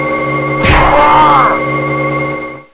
cycshot.mp3